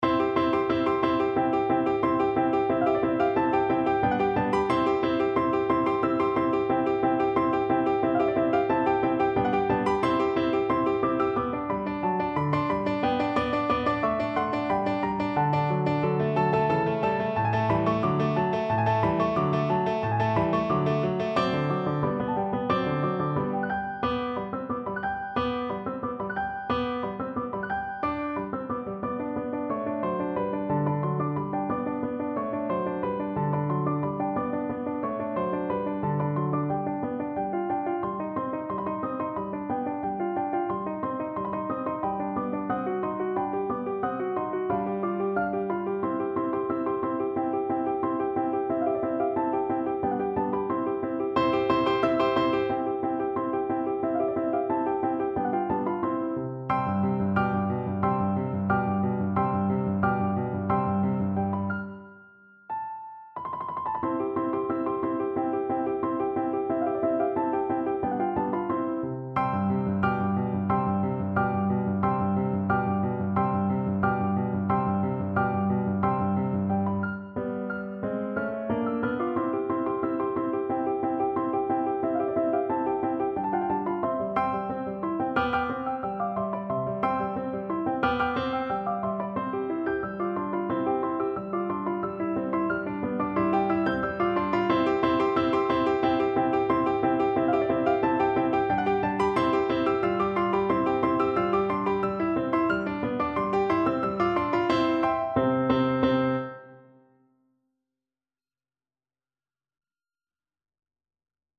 Play (or use space bar on your keyboard) Pause Music Playalong - Piano Accompaniment Playalong Band Accompaniment not yet available transpose reset tempo print settings full screen
French Horn
2/4 (View more 2/4 Music)
C major (Sounding Pitch) G major (French Horn in F) (View more C major Music for French Horn )
Presto =180 (View more music marked Presto)
Classical (View more Classical French Horn Music)